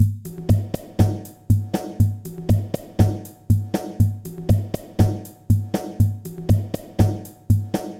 小鼓 " EZ BFD混合小鼓
描述：圈套，鼓套装tama dw ludwig打击乐打击样本鼓
Tag: 路德维希 小鼓 DW 撞击声 样品 试剂盒 多摩 打击